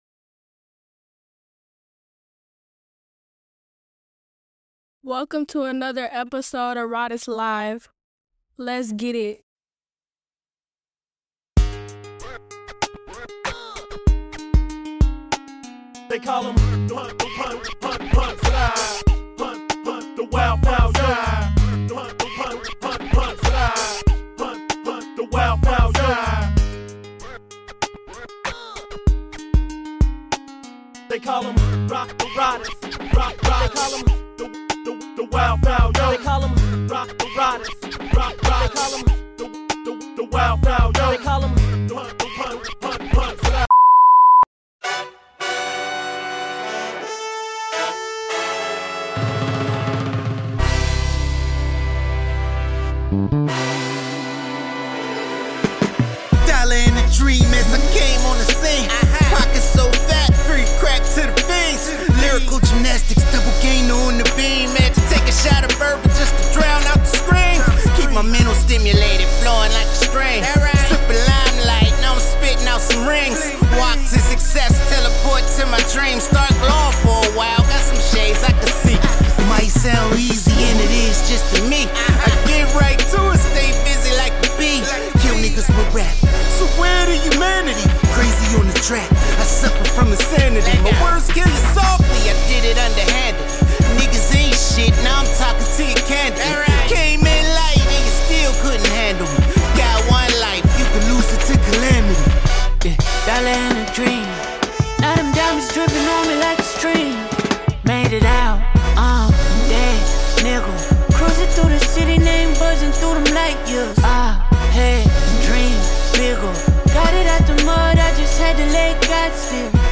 🎧 Tap in now and catch this powerful conversation about authenticity, hustle, and Hip-Hop evolution.